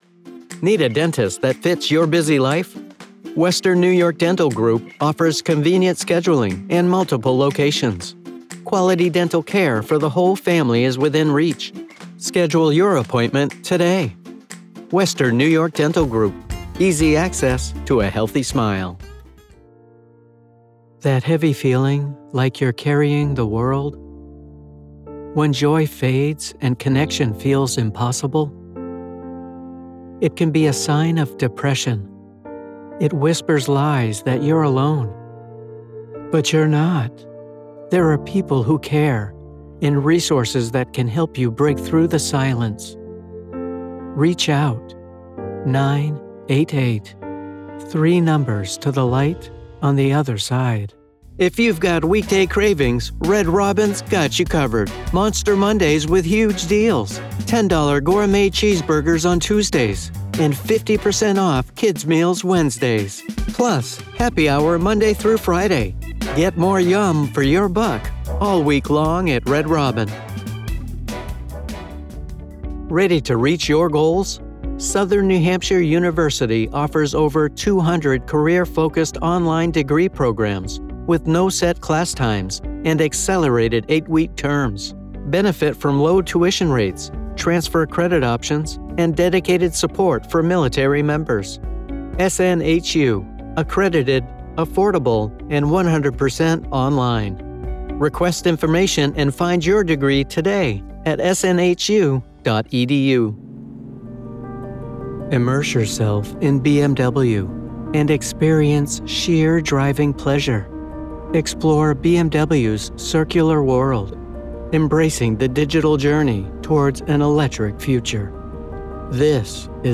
Male
I have a conversational tone that is natural, believable, and friendly. I have a naturally younger sounding voice but can provide an older and seasoned voice when needed.
Radio Commercials
Commercial Samples
0812FULL_COMM_DEMO_Mixdown.mp3